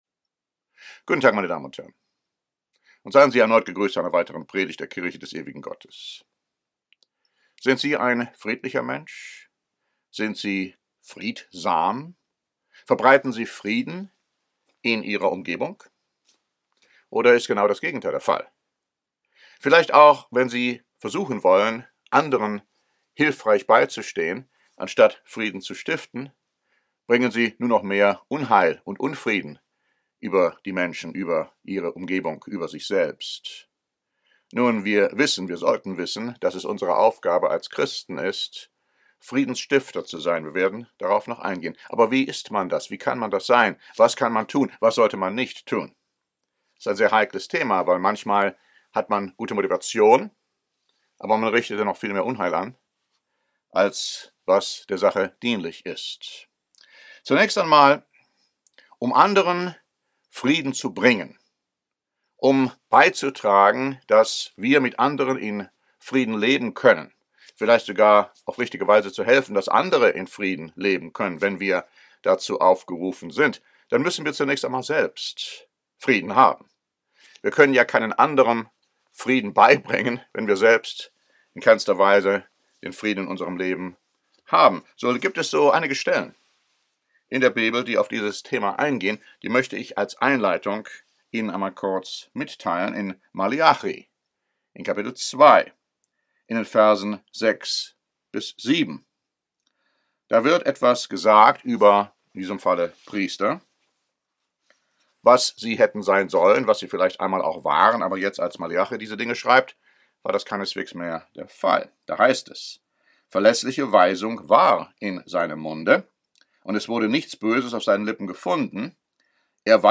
Diese Predigt zeigt die Prinzipien auf, die zu wahrem Frieden mit unserem Mitmenschen führen können.